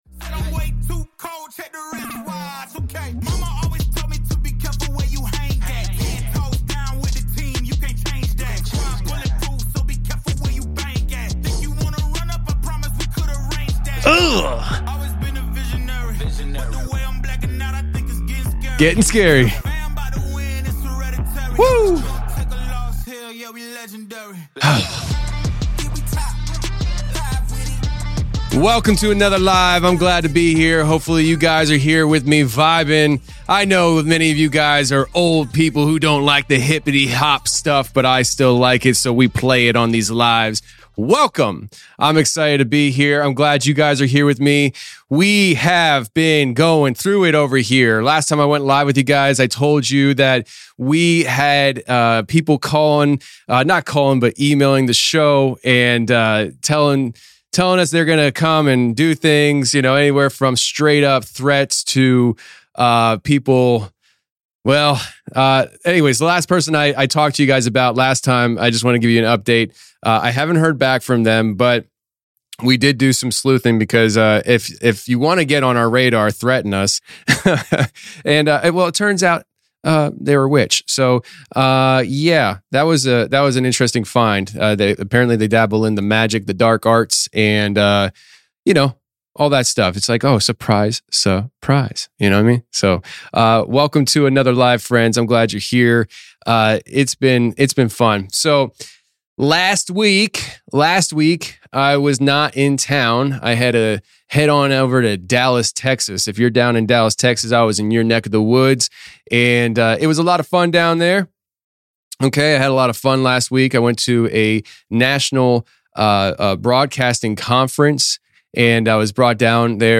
In this live discussion